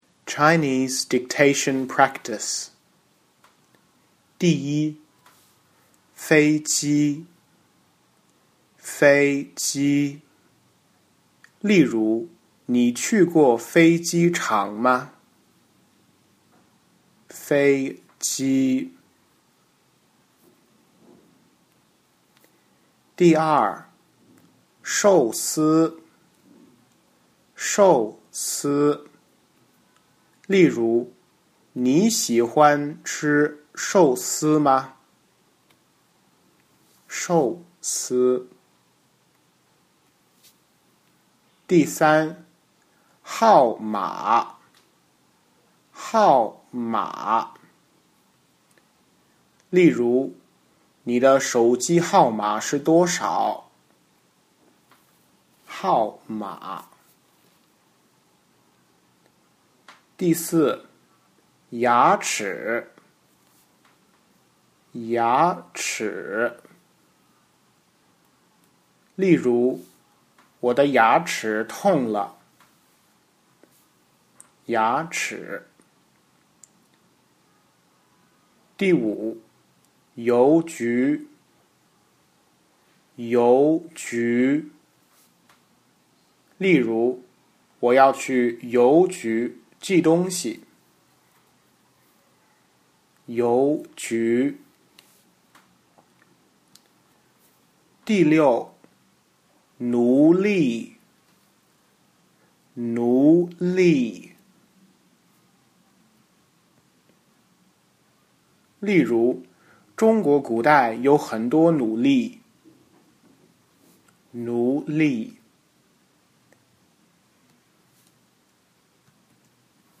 In the audio, you will hear the serial number for each item, followed by the word itself, a sentence employing the word, and the word again.
50-High-Frequency-Words-Chinese-Dictation-Practice.mp3